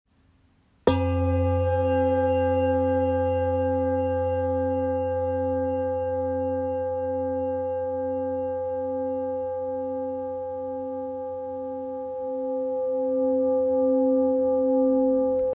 Master Series Singing Bowls 30 – 33cm
30cm Stick
Every Master Series Singing Bowl is made to deliver a powerful, harmonious sound.
With clarity, warmth, and a long-sustaining tone, these bowls create an enveloping resonance that enhances meditation, sound baths, and therapeutic sessions.